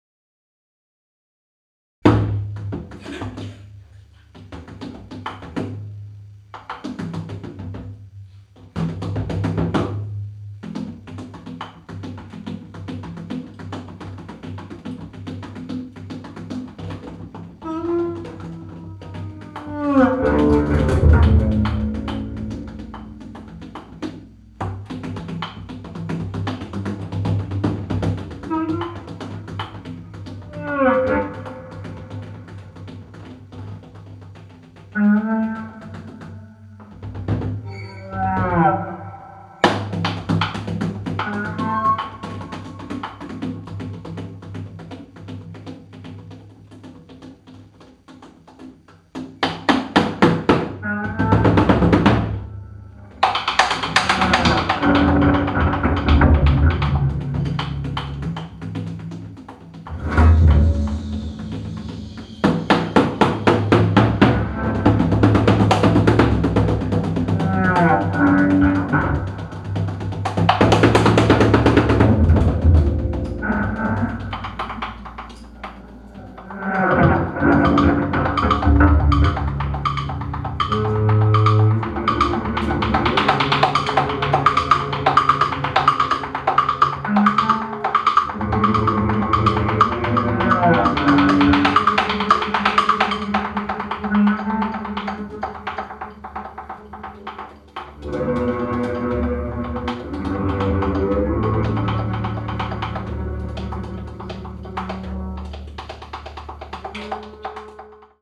free jazz and improvised music
homemade electric vertical five strings bass
percussion